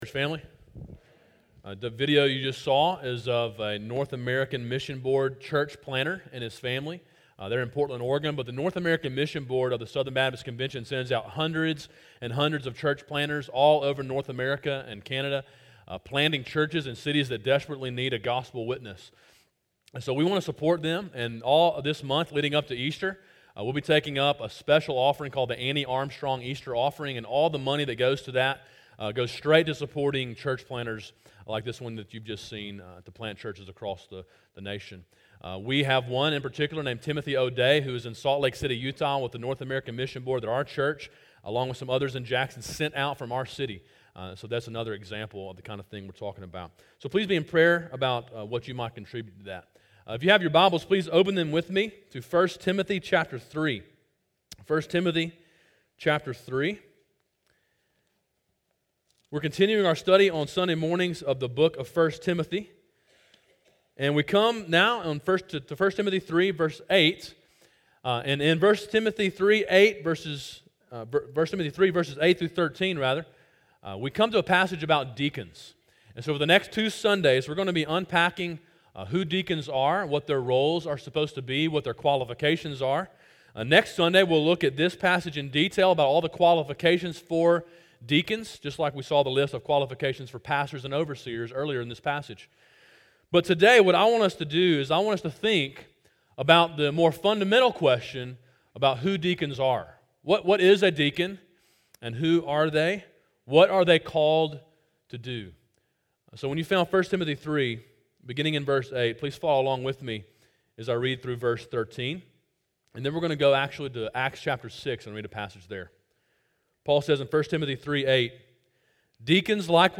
A sermon in a series on the book of 1 Timothy.